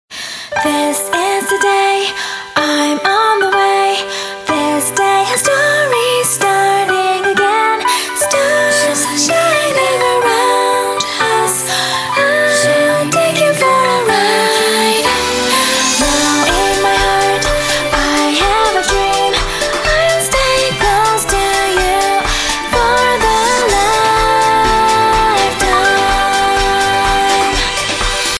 很可爱的婚礼歌曲-网络歌手.mp3